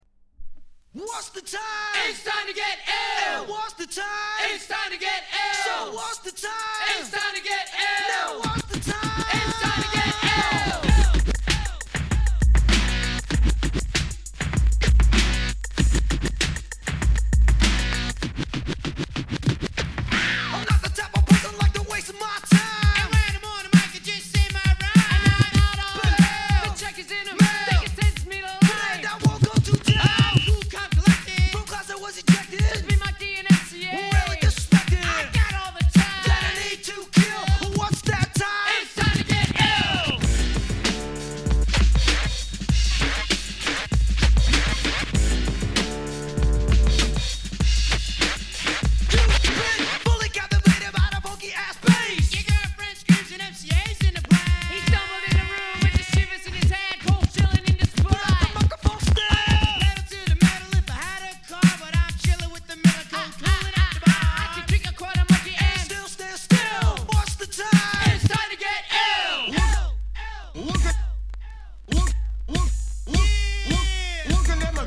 category Rap & Hip-Hop